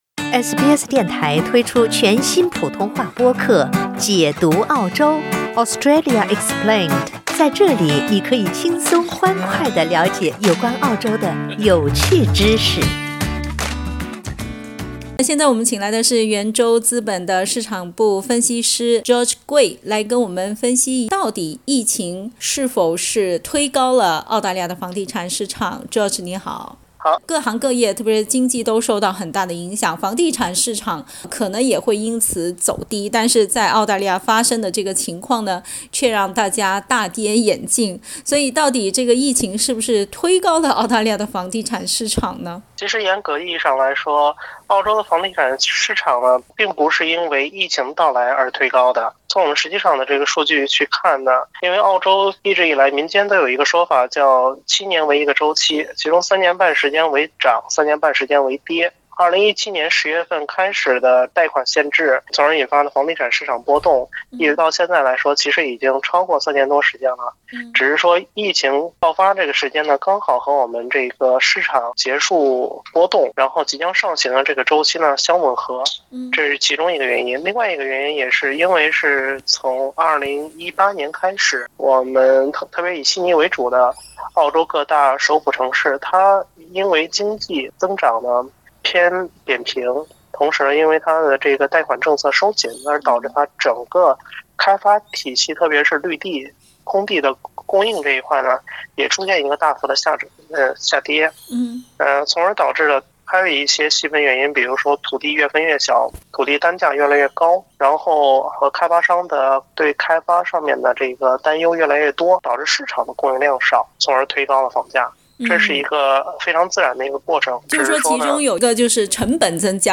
（请听采访，本节目为嘉宾观点，仅供参考） 澳大利亚人必须与他人保持至少1.5米的社交距离，请查看您所在州或领地的最新社交限制措施。